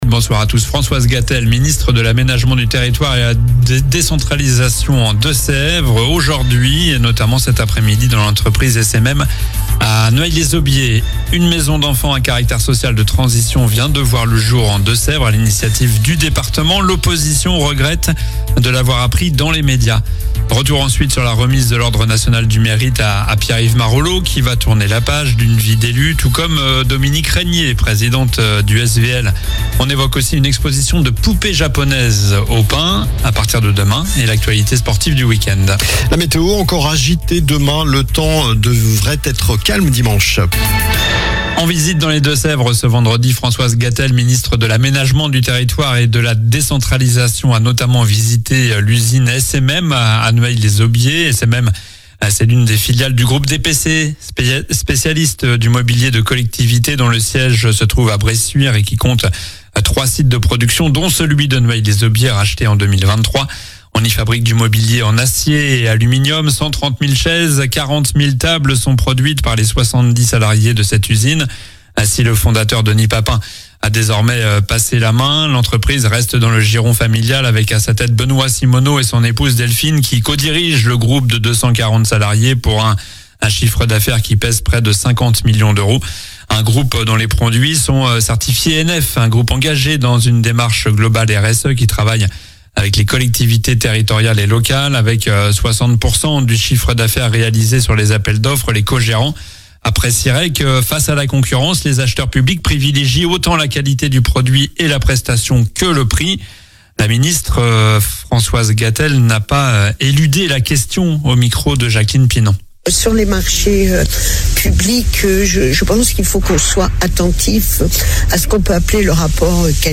Journal du vendredi 6 février (soir)